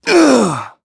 Clause_ice-Vox_Damage_02.wav